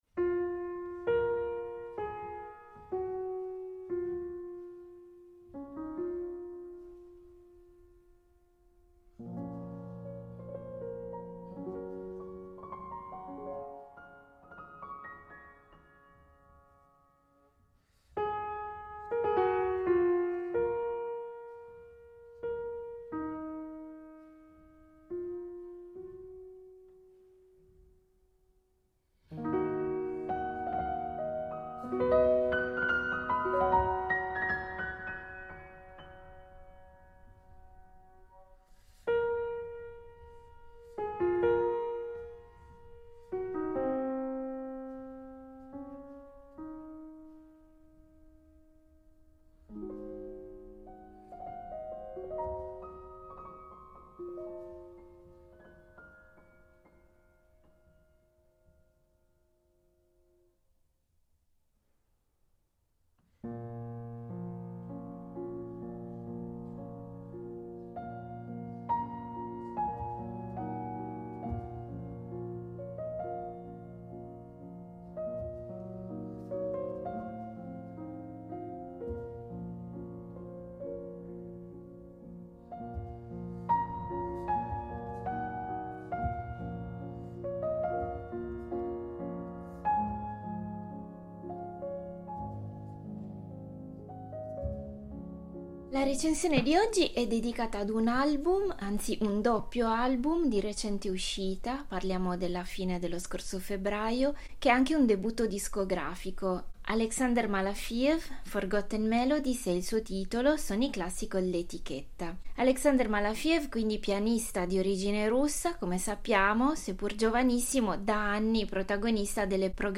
Il suo pianismo intenso quanto energico unisce il rigore della scuola russa - si forma tra la Gnessin Special School e il Conservatorio Tchaikovsky e nel 2014, appena tredicenne, vince il primo premio dell’International Tchaikovsky Competition per giovani musicisti - ad estro, passione travolgente e brillantezza di suono.